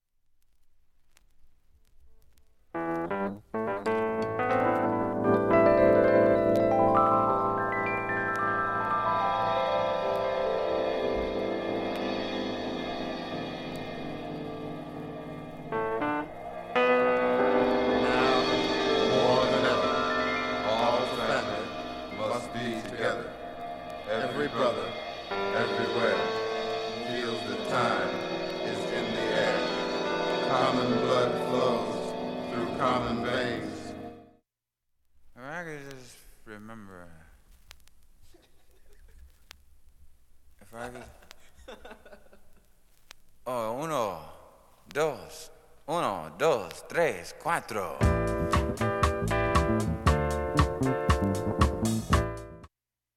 クリアな音質良好全曲試聴済み。
A-1始めにかすかなプツが７回と２回と３回出ます。
聴き取り出来ないレベルです。
ゲットー・メロウ・ジャズ
メロウジャズダンサー